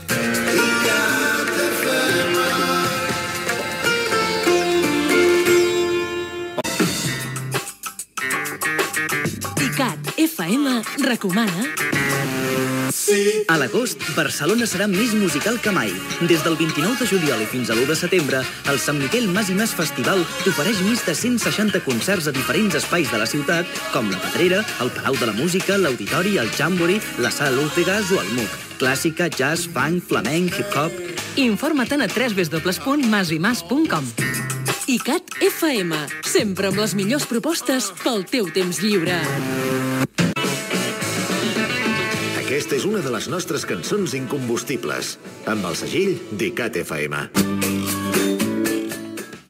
Indicatiu, anunci del San Miguel Mas y Mas Festival, a Barcelona, i tema musical